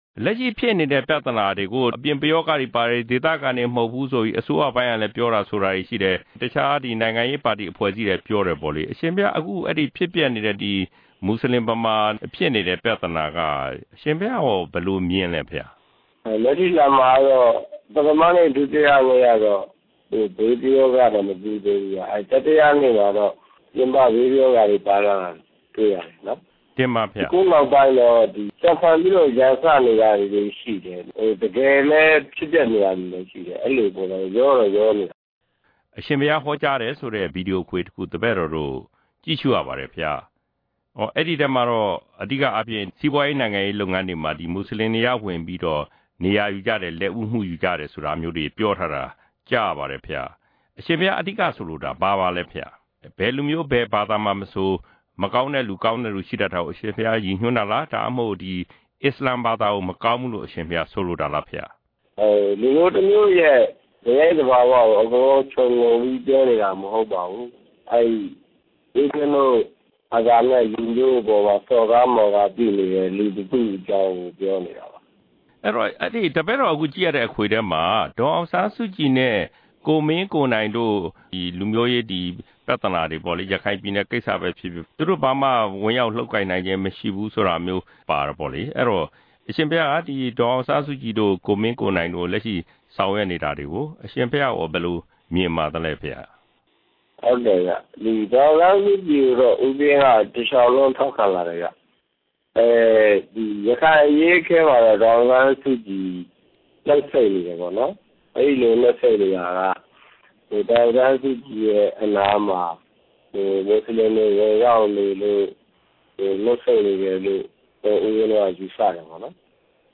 မစိုးရိမ်ကျောင်းတိုက် ဆရာတော် အရှင်ဝီရသူရဲ့ မိန့်ကြားချက်